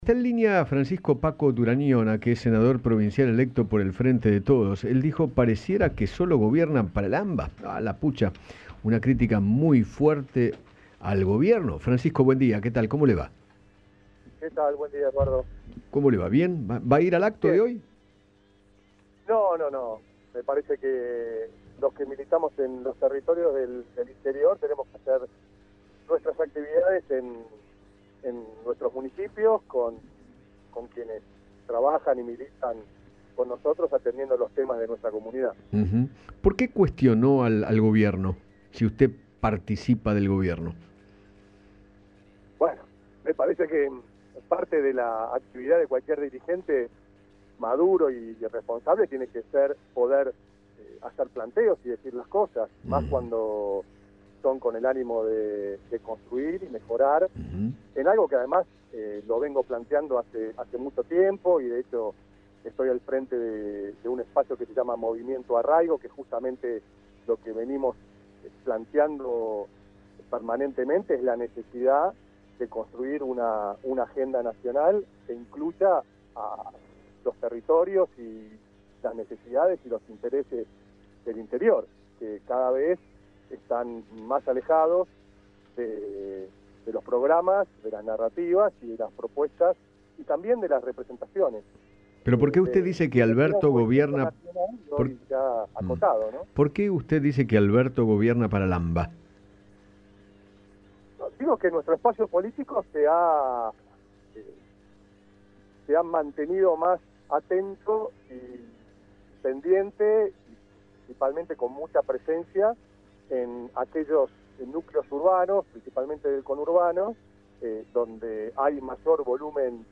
Francisco Paco Durañona, senador provincial, conversó con Eduardo Feinmann sobre el acto que encabezará Alberto Fernández por el Dia de la Militancia y criticó al Gobierno por la falta de inclusión del interior en varias decisiones y medidas.